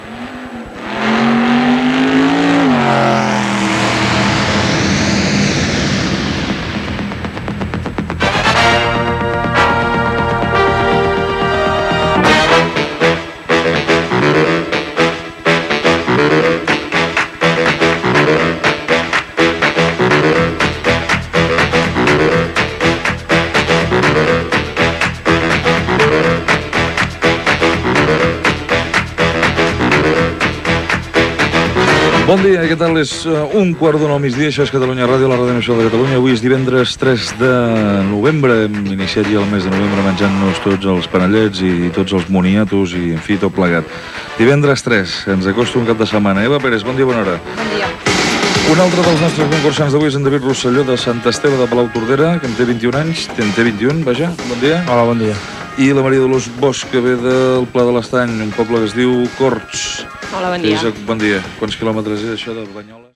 Sintonia i presentació del programa i dels concursants.
Entreteniment